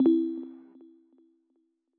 scoge-menu1-enter-1.wav